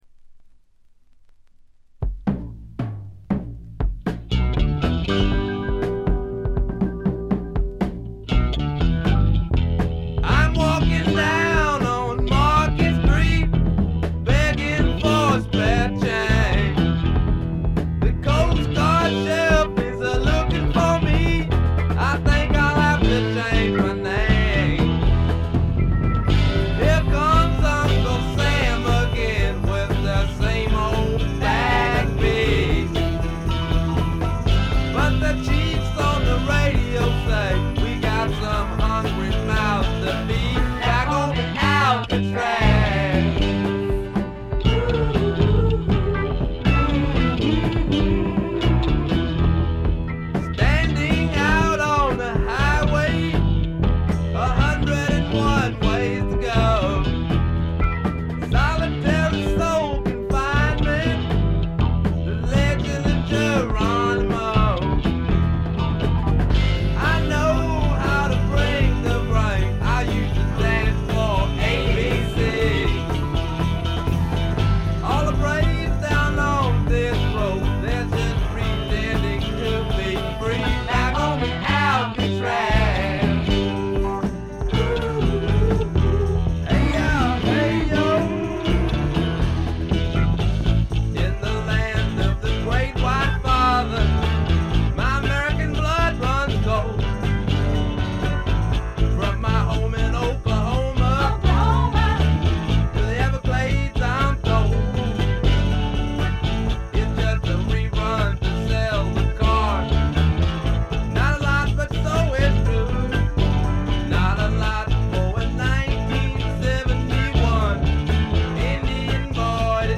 軽微なチリプチがほんの少し。
いうまでもなく米国スワンプ基本中の基本。
試聴曲は現品からの取り込み音源です。